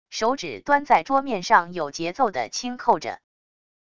手指端在桌面上有节奏地轻扣着wav音频